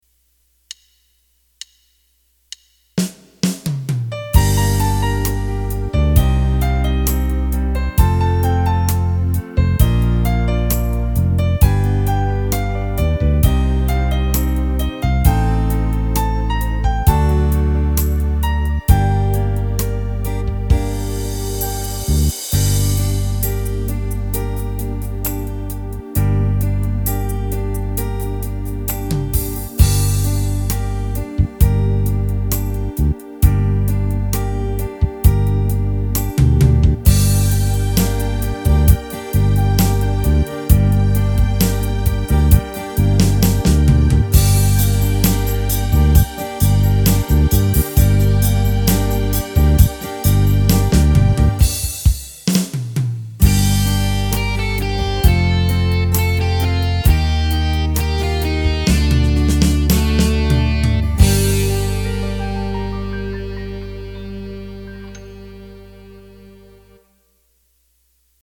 (Gm) Slowsurf - Female